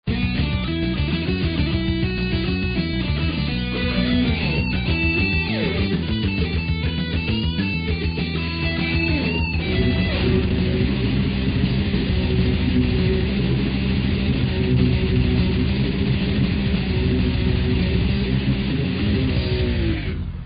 勝手にアレンジしてしまいました。